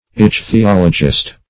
Ichthyologist \Ich`thy*ol"o*gist\, n. [Cf. F. ichthyologiste.]